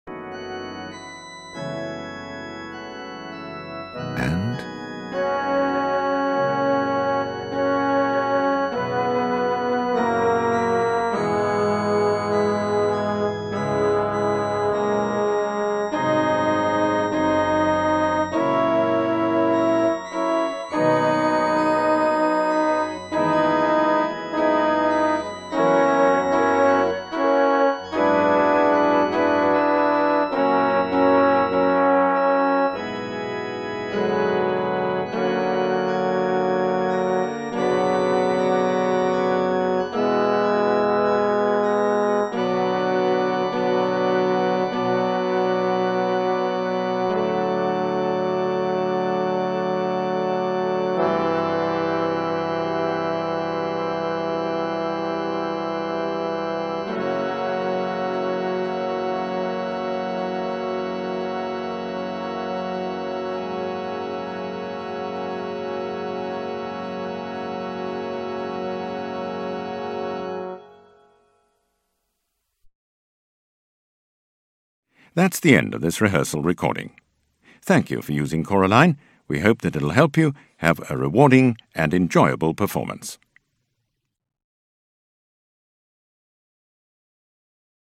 There is no text, just your part.
2nd Tenor